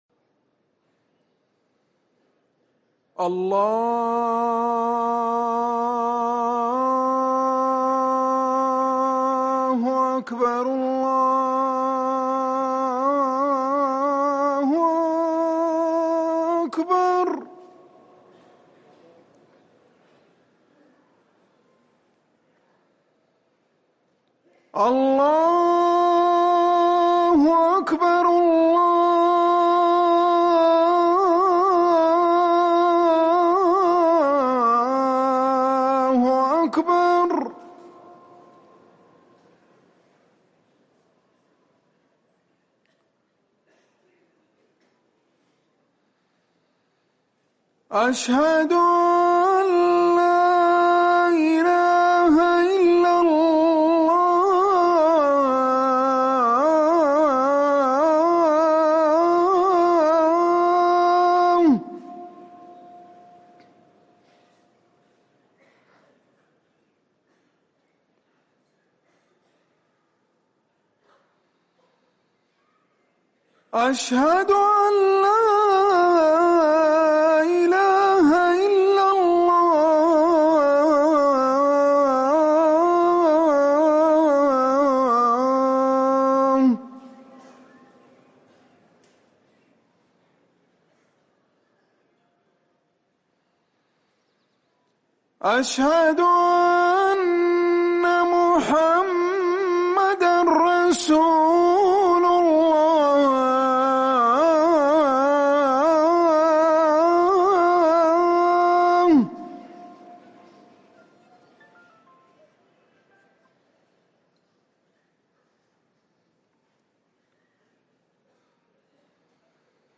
اذان العصر